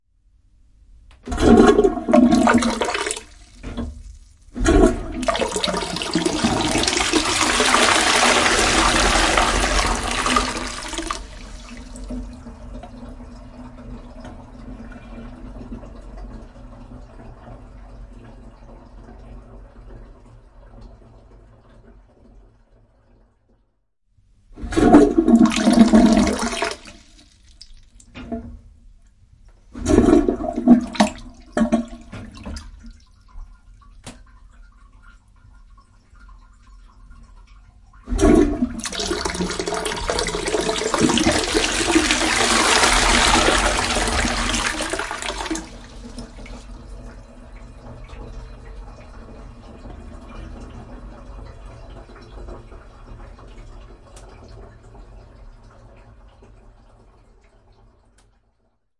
马桶冲水和加水
描述：卫生间冲洗有趣，环境，水补充声音。
标签： 冲洗 马桶冲洗 厕所 水冲洗 厕所 水笔芯
声道立体声